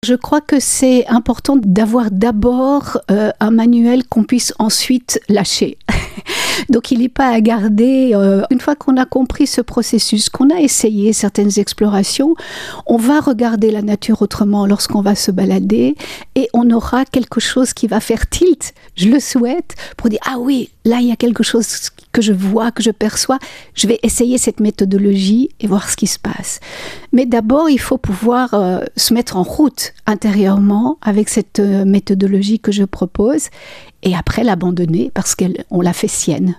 Merci aux journalistes de la radio RJB, aux infos du 10 et 11 septembre 2025.